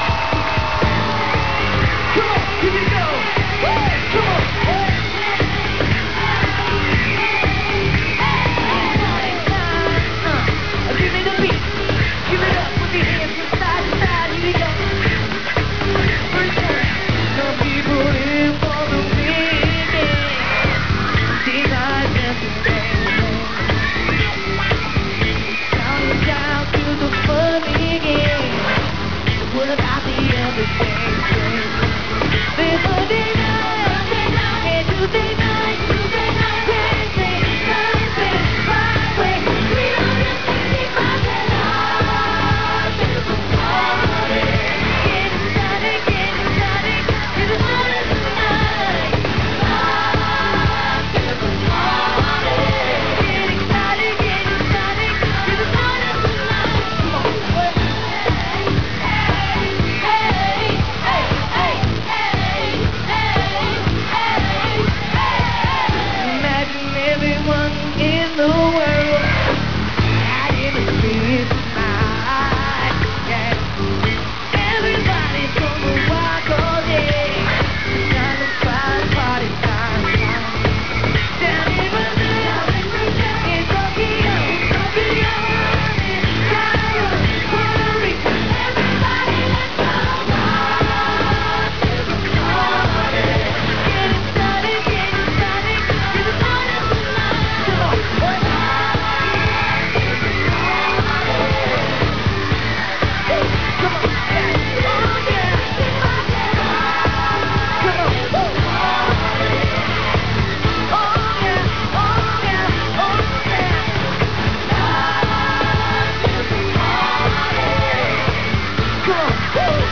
Sounds Recorded From TV Shows